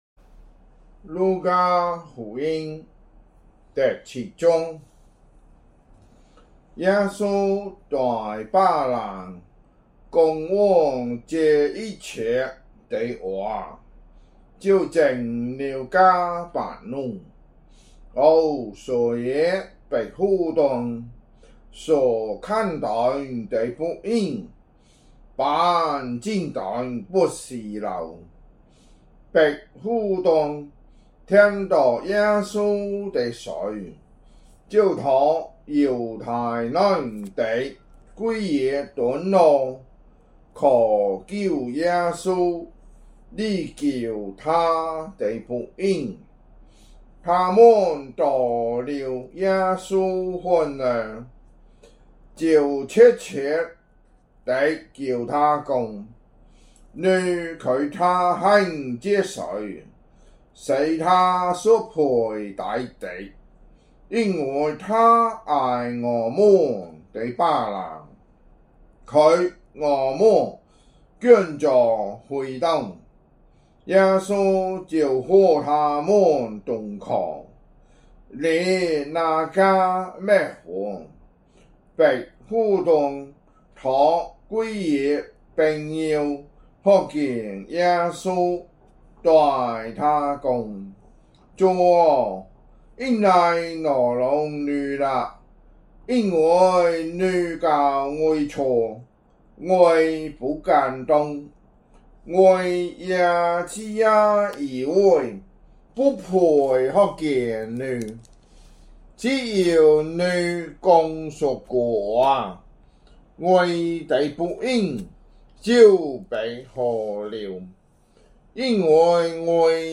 福州話有聲聖經 路加福音 7章